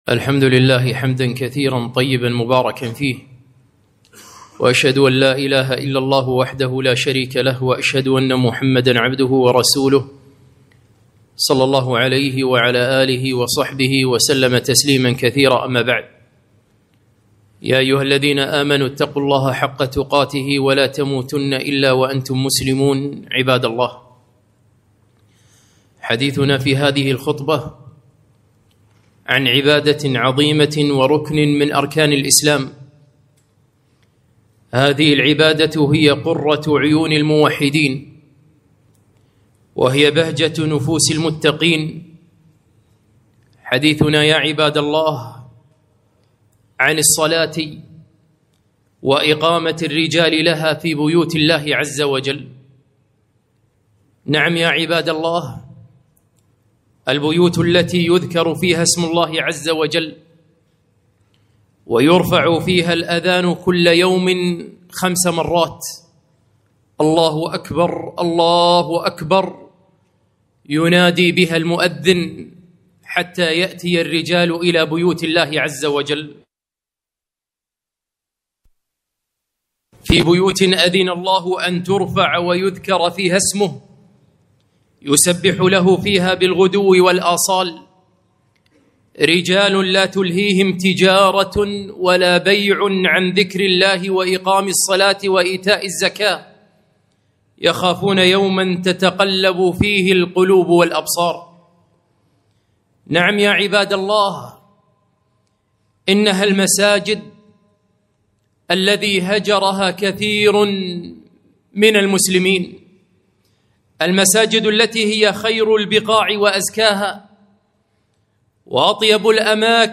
خطبة - أهمية صلاة الجماعة